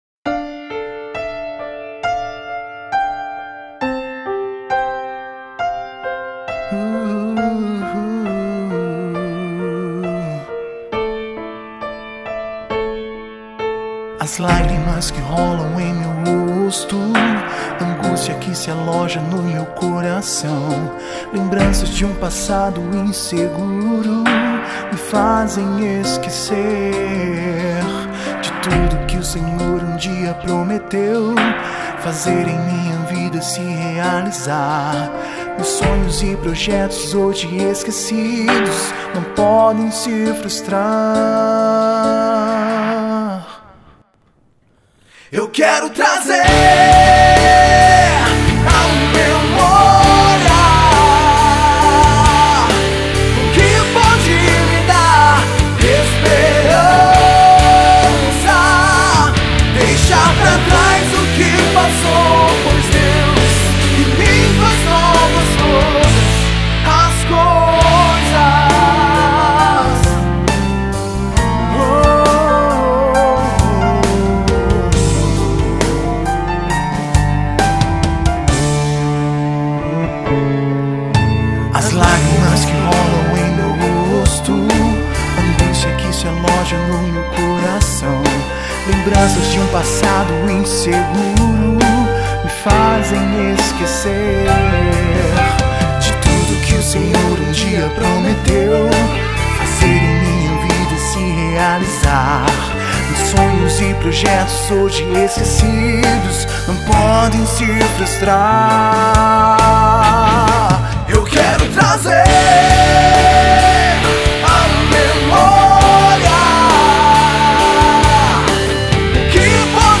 que traz um som pop rock.